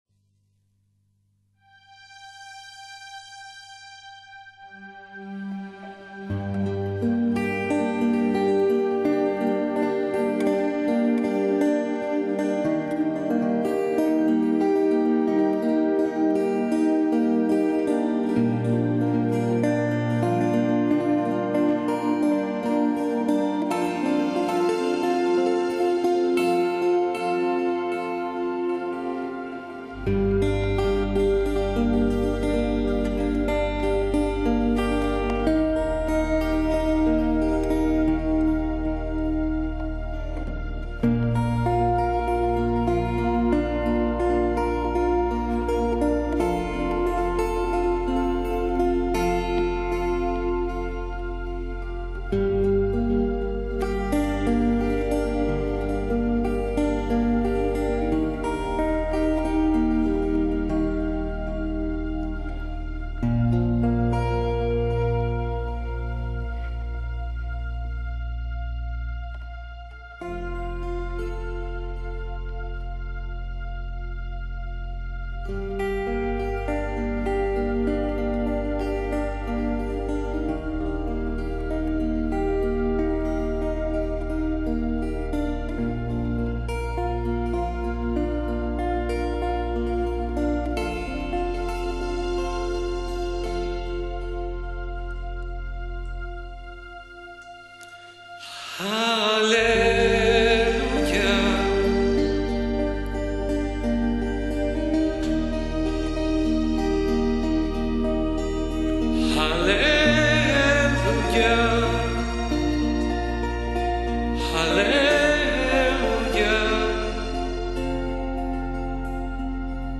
Grabacion Adoracion de cabalat shabat
Shalom de YESHUA el Meshiaj este Shabat, en este enlace encontrar�n la adoraci�n a la hora de la tarde con la cual recibimos el Shabat ac� en Yerushalaim hoy 12, de Jul de 19:40 a 21:05 aprox.